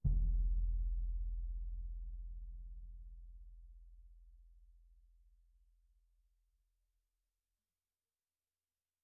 bassdrum_hit_pp2.wav